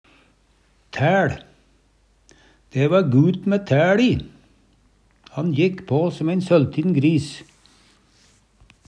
tæL - Numedalsmål (en-US)